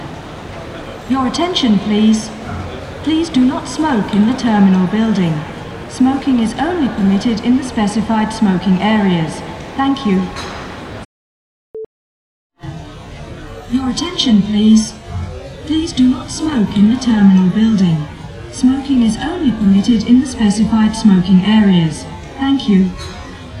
If you have a stereo recording you can remove the echo/reverb by centre pan isolation (Kn0ck0ut)
The voice is common to both channels but typically the echoes reach the different channels at different times so are removed by centre pan isolation, (a.k.a. centre pan extraction).